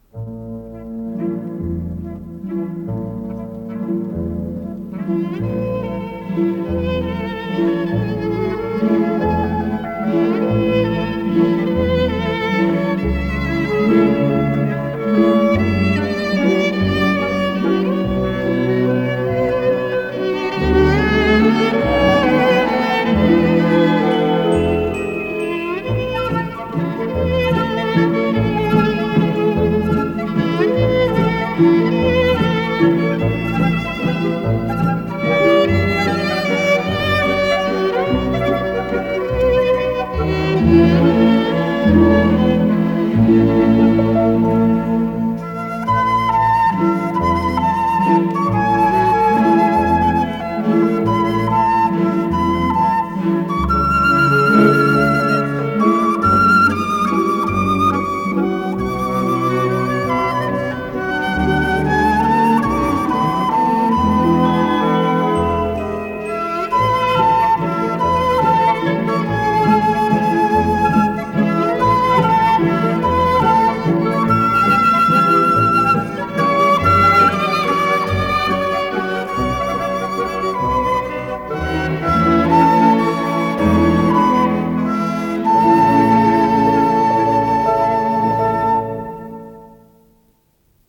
ПодзаголовокЗаставка, ми бемоль мажор
ВариантДубль моно